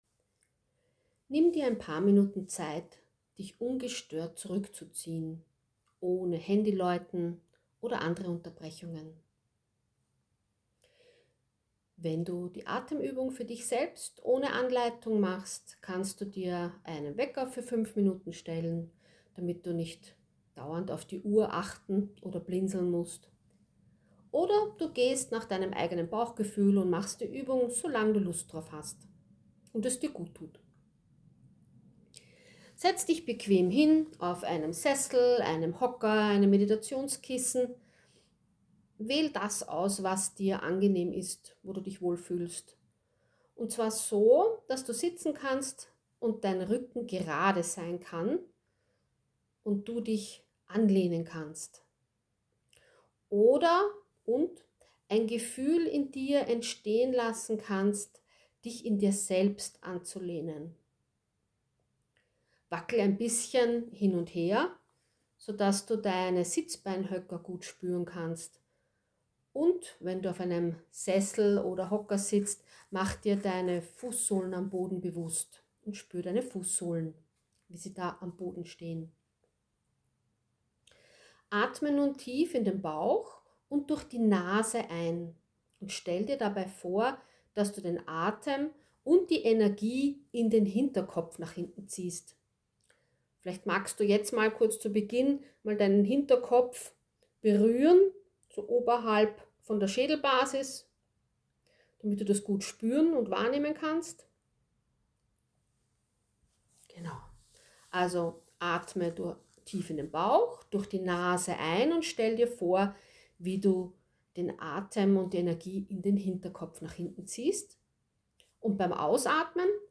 Mache diese kurze SOS-Atemübung gemeinsam und beruhige Dein Nervensystem.